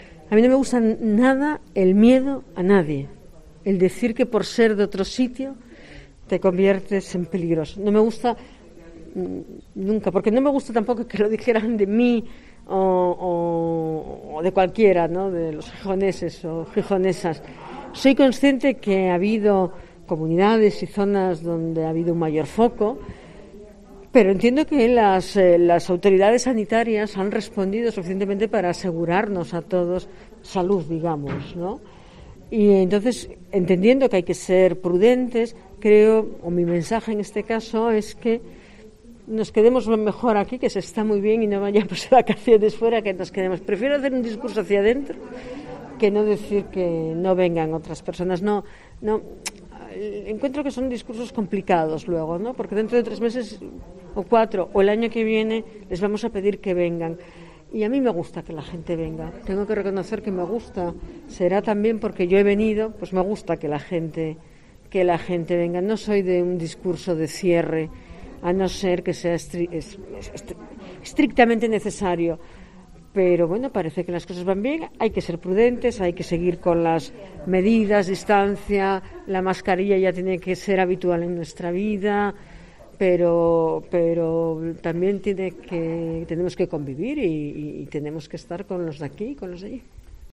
Nos dicen un grupo de paseantes por el Muro de San Lorenzo.